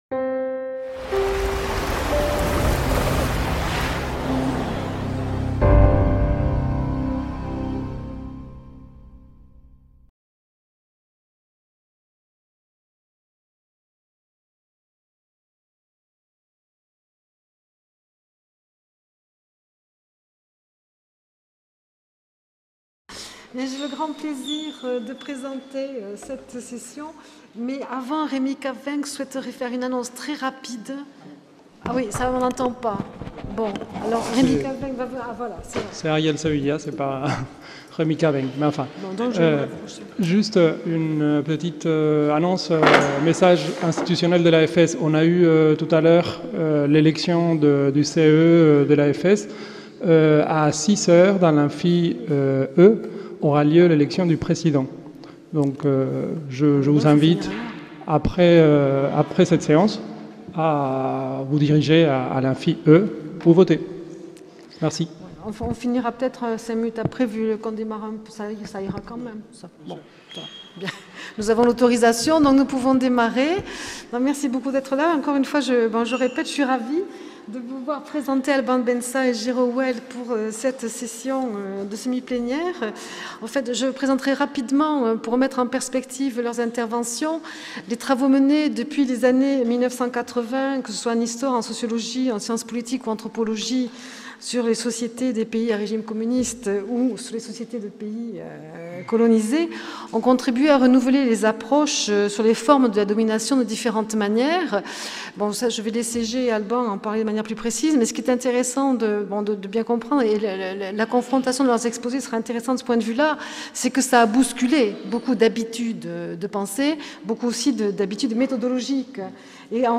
L'UFR de Sociologie et le Centre Nantais de Sociologie (CENS) de l’Université de Nantes accueillaient du 2 au 5 septembre 2013 le 5e congrès international de l'association française de sociologie.